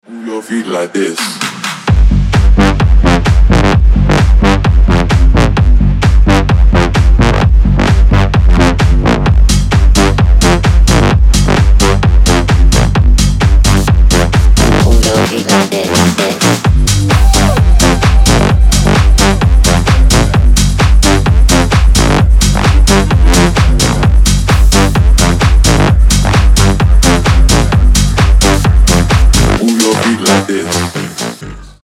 Крутой качающий трек